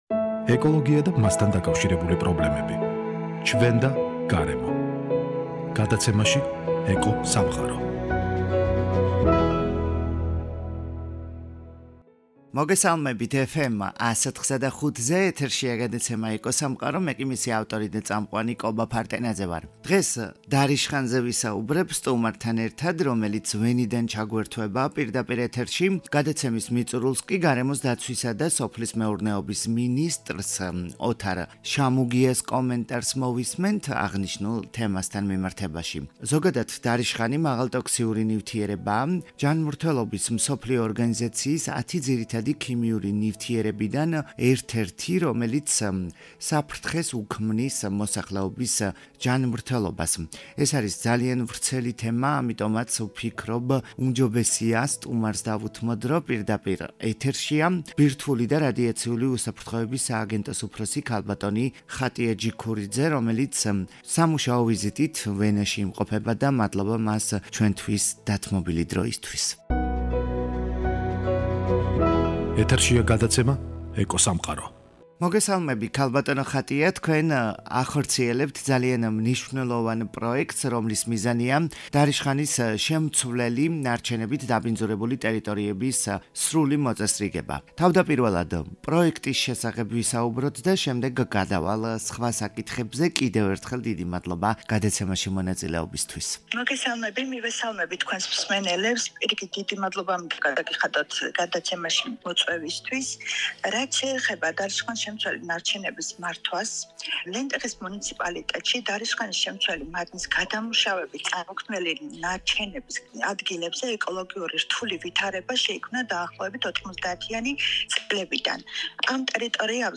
დარიშხანზე ვსაუბრობ გადაცემაში სტუმართან ერთად, რომელიც ვენიდან გვერთვება, ბირთვული და რადიაციული უსაფრთოების სააგენტოს უფროსი ქალბატონი - ხატია ჯიქურიძე, გადაცემის მიწურულს კი გარემოსა დაცვისა და სოფლის მეურნეობის მინისტრის - ოთარ შამუგიას კომენტარს მოვისმენთ აღნიშნულ თემასთან მიმართებაში. ზოგადად დრიშხანი მაღალტოქსიკურობის მქონე ნივთიერებაა, ჯანდაცვის მსოფლიო ორგანიზაციის 10 ძირითადი ქიმიური ნივთიერებებიდან  ერთერთი,